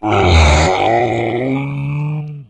controller_hit_0.ogg